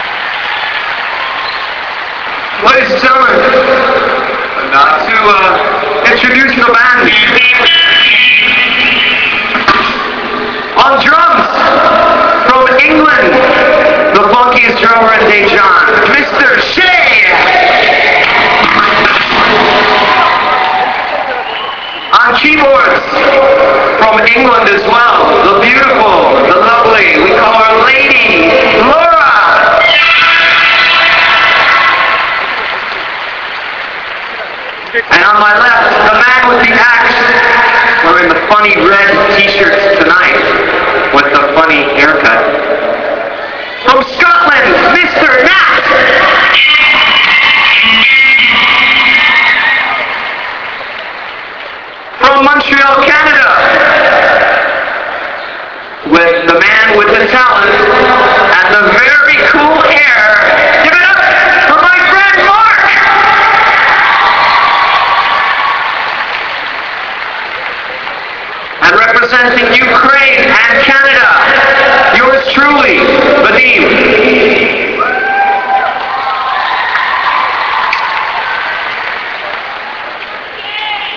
The Stadium Concert
I'd received the news about a week before that Ten Legs (the band formerly known as The Nomadz) were going to be playing a show at Daejon stadium.
They played a pretty mean set and got a great response from the audience.